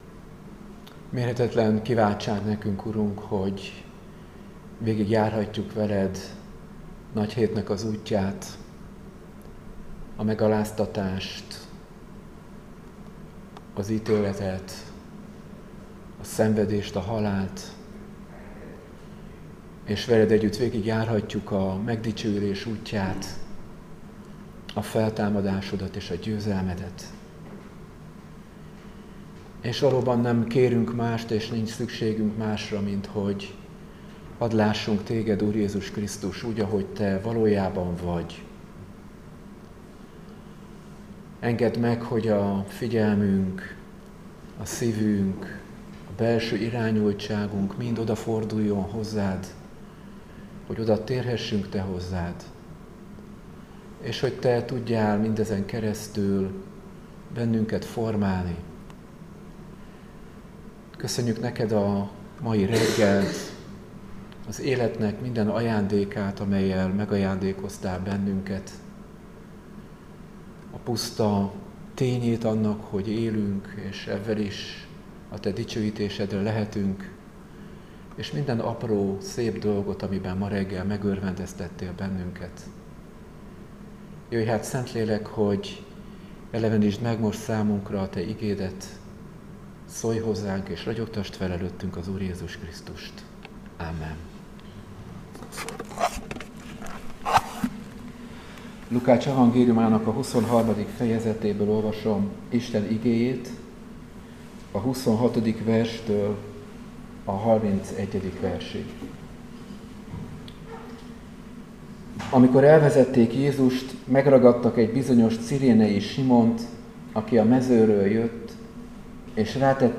Áhítat, 2025. április 15.